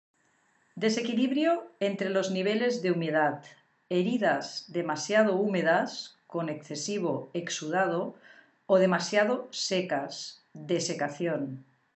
Learners may also listen to audio files to hear the right pronunciation of a term.